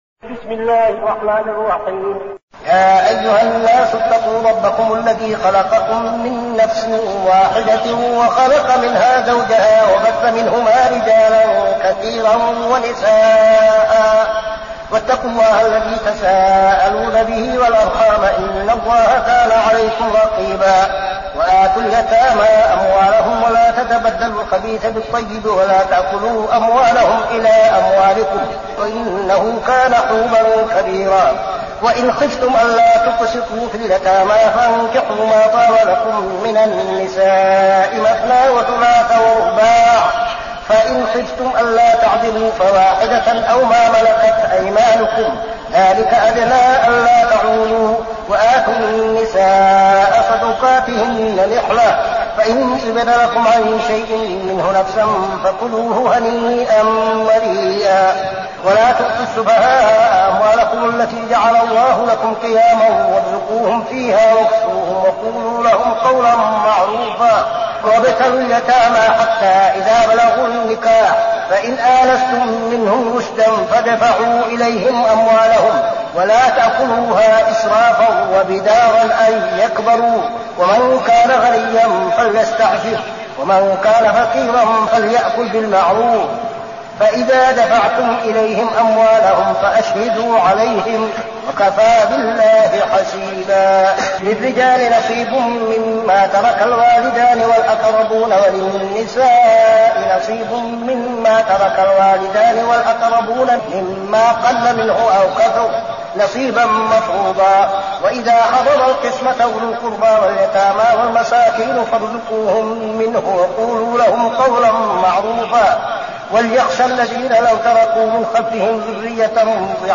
المكان: المسجد النبوي الشيخ: فضيلة الشيخ عبدالعزيز بن صالح فضيلة الشيخ عبدالعزيز بن صالح النساء